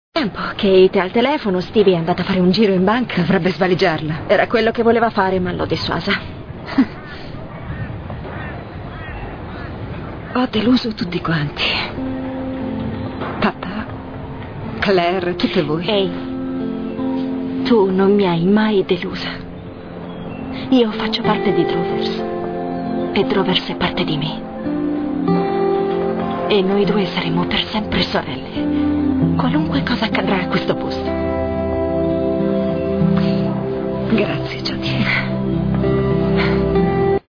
dal telefilm "Le sorelle McLeod", in cui doppia Rachel Carpani.